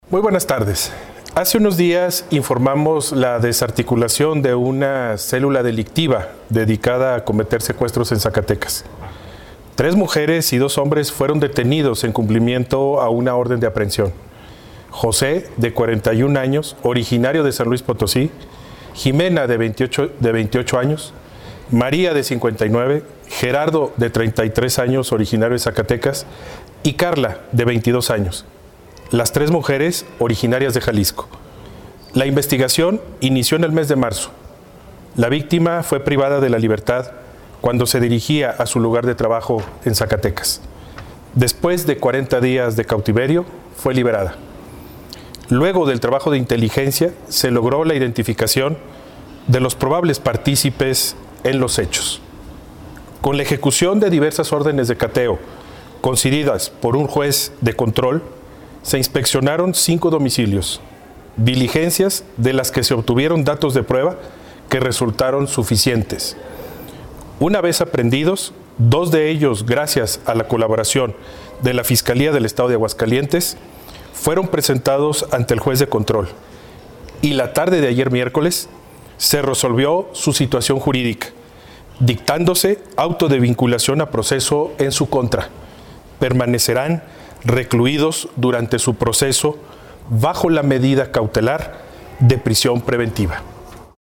AUDIO_MENSAJE_FISCAL_4_MAYO_2023.mp3